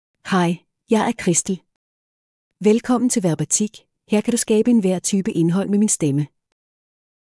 FemaleDanish (Denmark)
Christel — Female Danish AI voice
Christel is a female AI voice for Danish (Denmark).
Voice sample
Christel delivers clear pronunciation with authentic Denmark Danish intonation, making your content sound professionally produced.